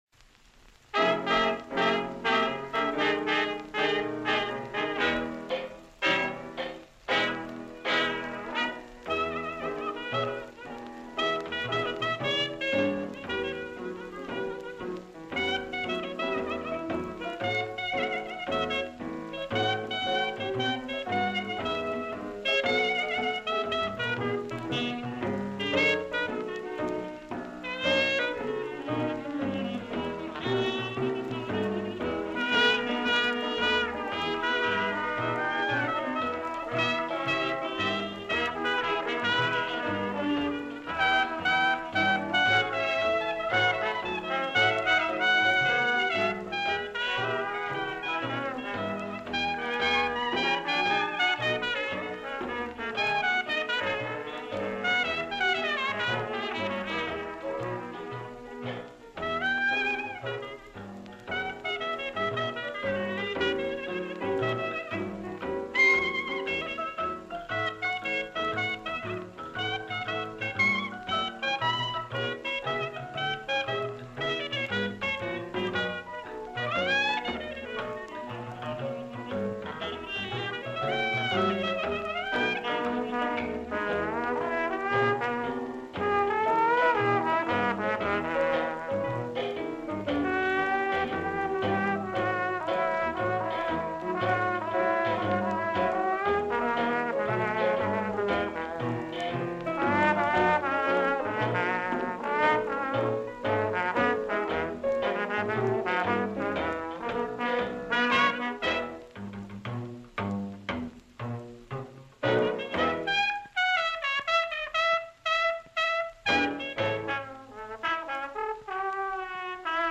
KGftnIXmALM_AMBIANCEBISTROTVIEUX.mp3